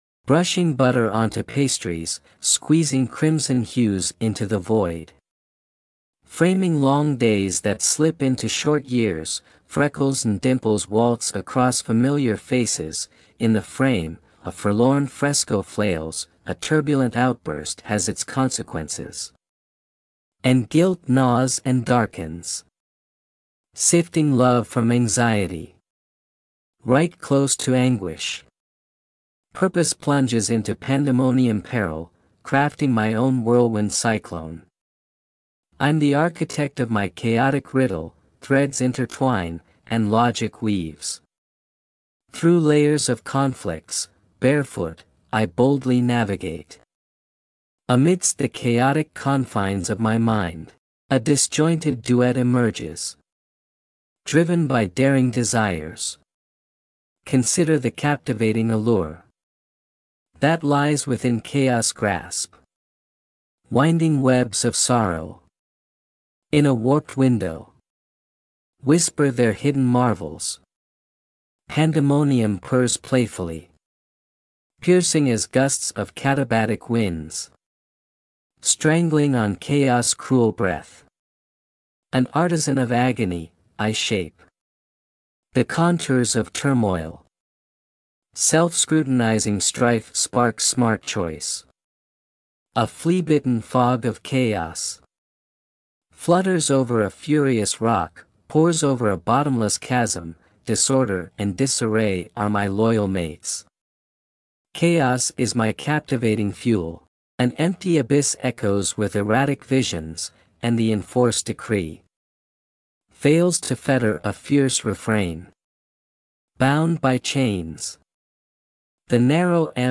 Your choice of music is making a great afternoon for me!